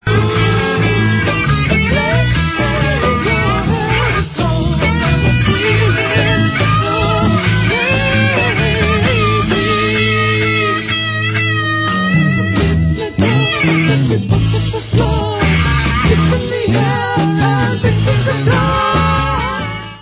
LOST CLASSIC OF 60'S UK ACID FOLK/BAROQUE PSYCHEDELICA